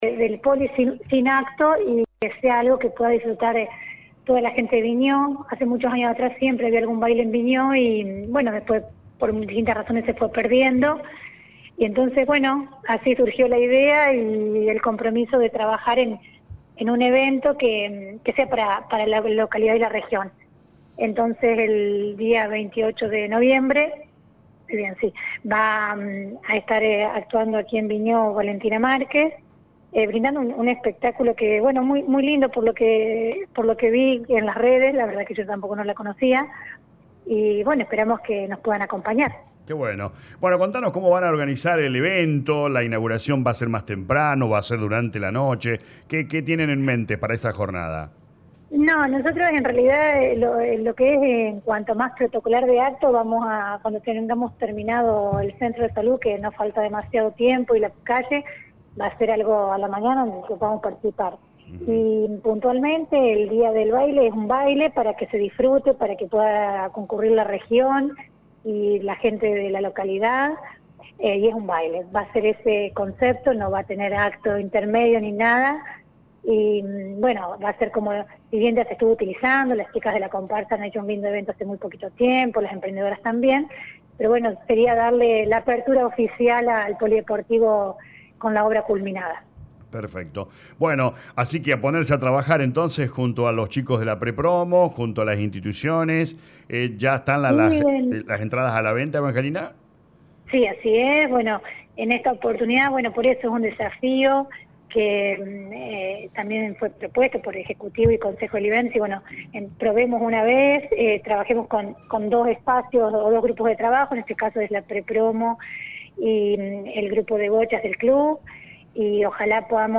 En diálogo con LA RADIO 102.9 FM la intendente de Colonia Vignaud Lic. Evangelina Vigna brindó detalles sobre la organización de este importante espectáculo para toda la reigón: